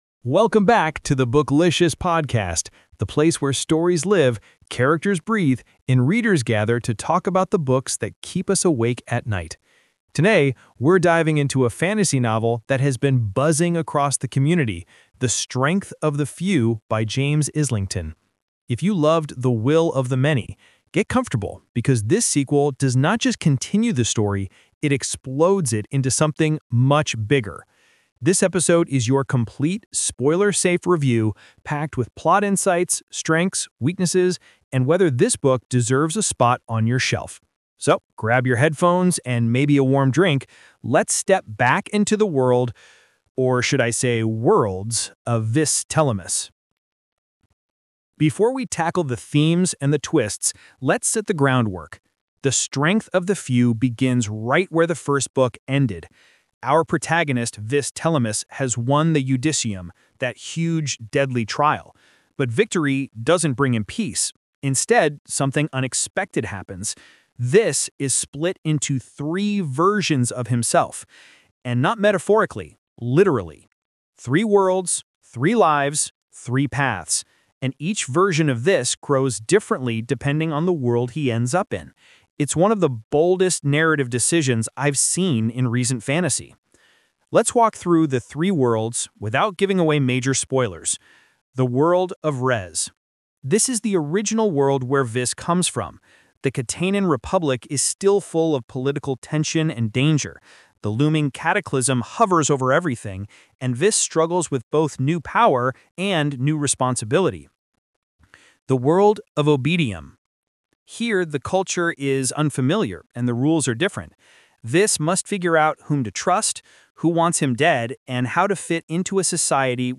The Strength of the Few: By James Islington | Book Review Podcast